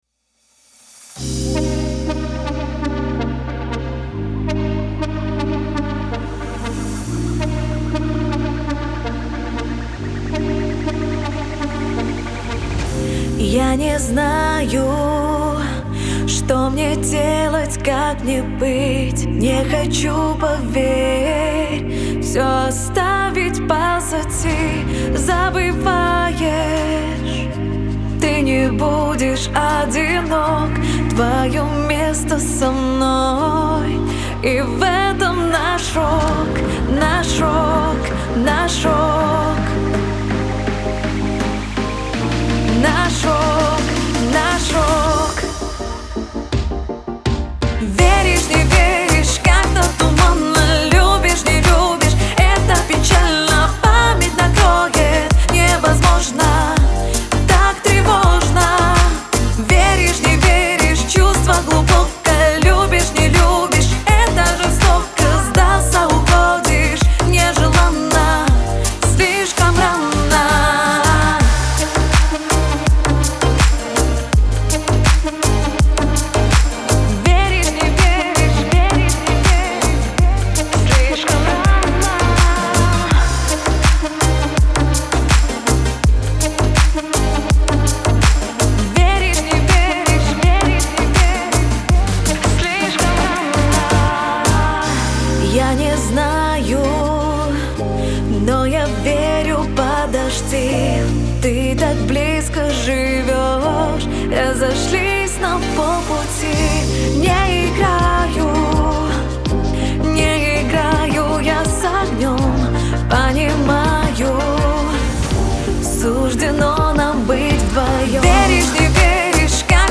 яркая и эмоциональная песня
использует выразительный вокал и современное звучание